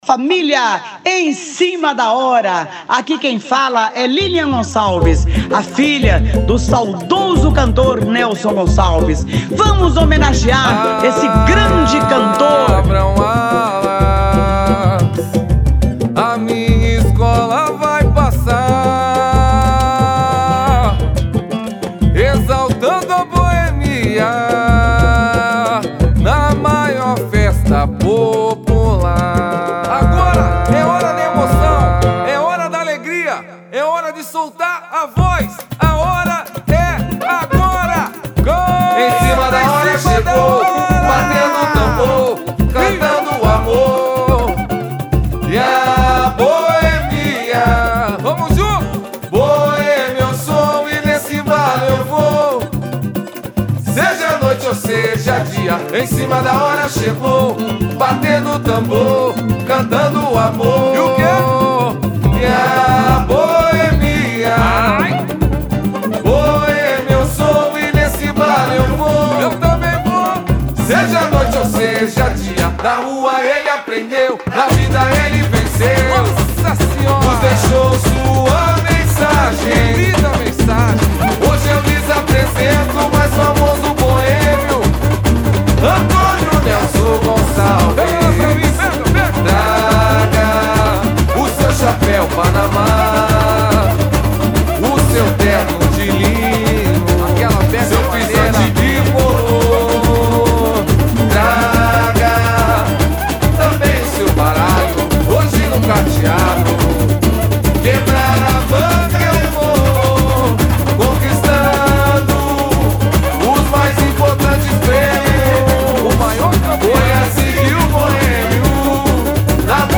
Samba Enredo 2019